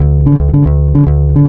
JAZZBASS  -R.wav